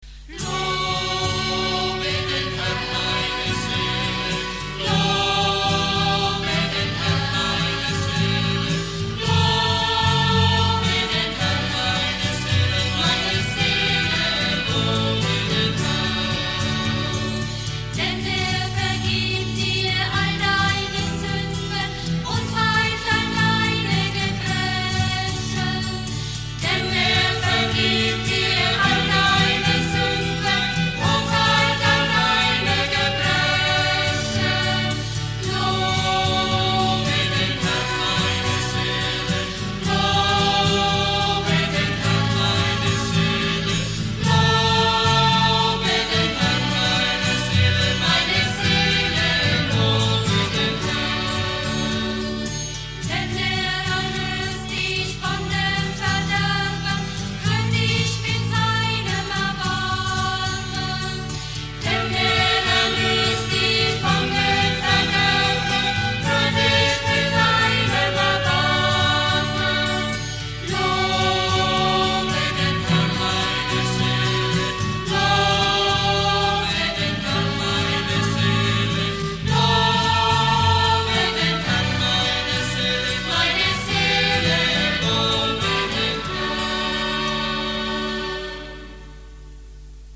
Startet zuerst diesen Lobpreis: